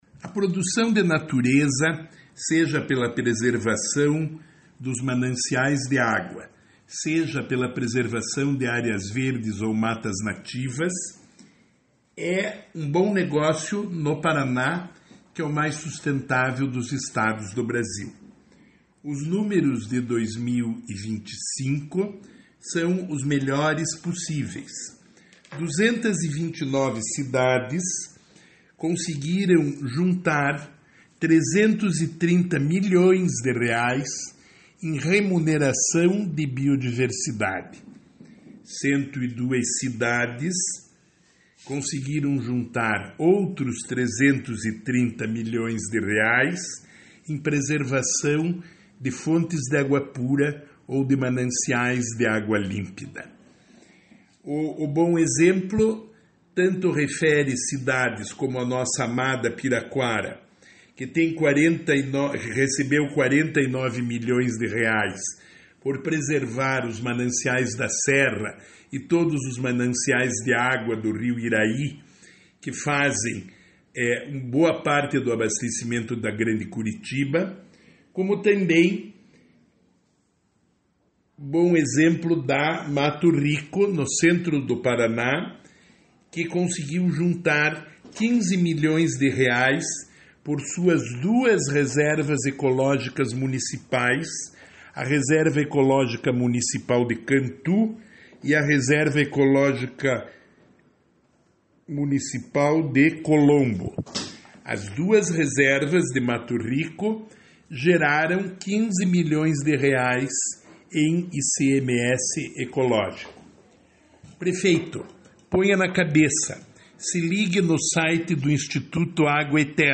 Sonora do secretário Estadual do Desenvolvimento Sustentável, Rafael Greca, sobre o ICMS Ecológico em 2025